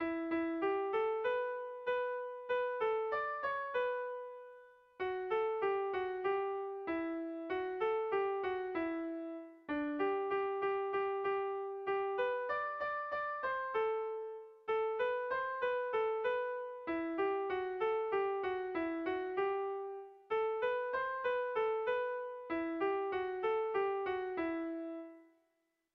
Irrizkoa
Hamarreko txikia (hg) / Bost puntuko txikia (ip)
ABDE